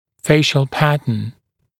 [‘feɪʃl ‘pætn][‘фэйшл ‘пэтн]тип строения лица